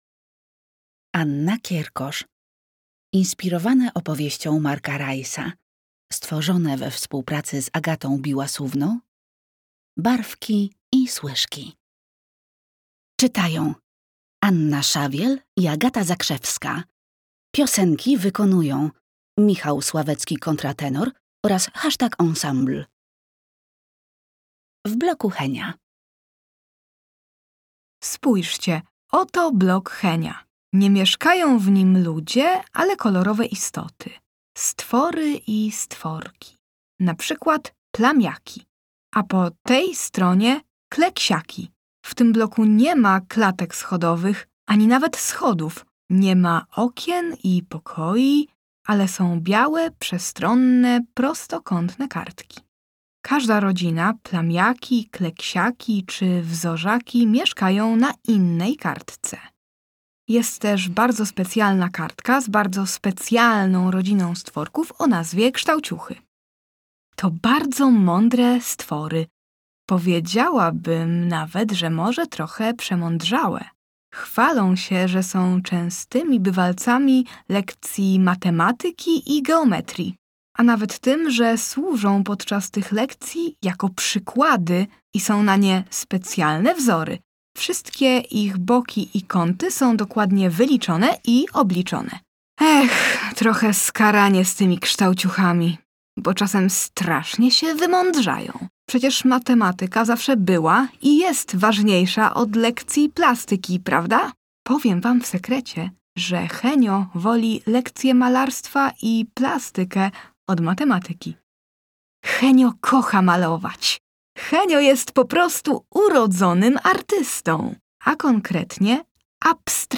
barwki-i-slyszki-audiodeskrypcja-.mp3